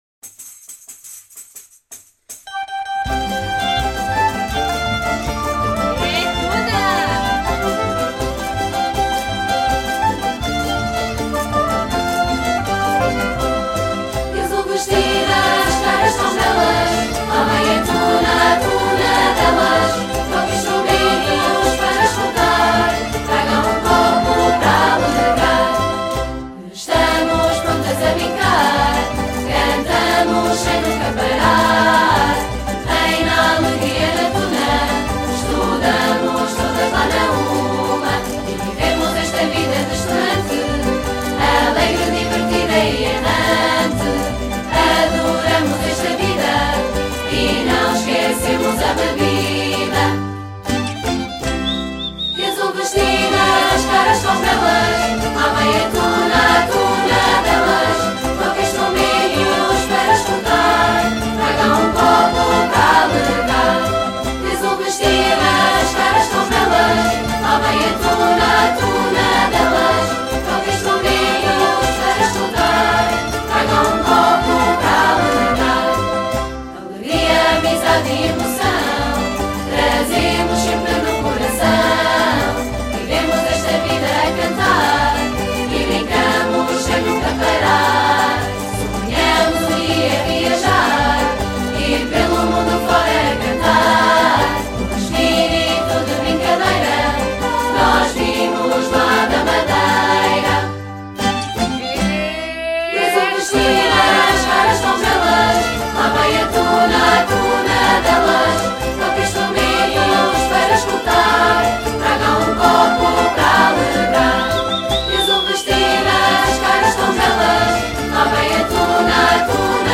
CANÇÃO ACADÉMICA